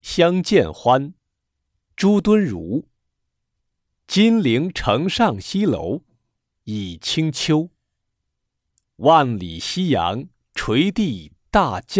八年级语文上册 第六单元课外古诗词诵读《相见欢》课文朗读素材